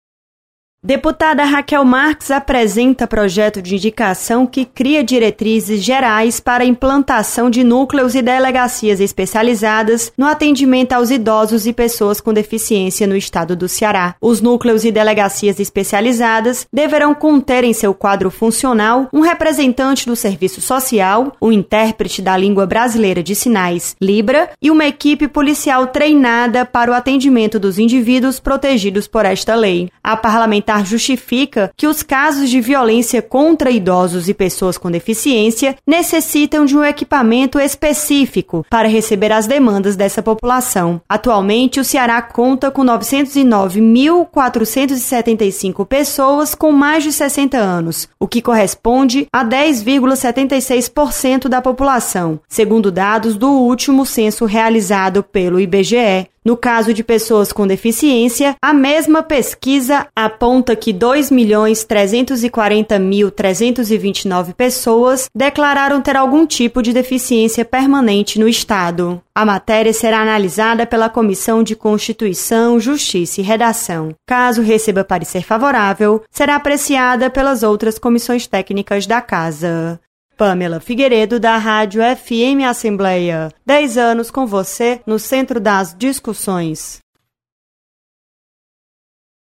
Projeto prevê criação de núcleos e delegacias especializadas no atendimento aos idosos e pessoas com deficiência. Repórter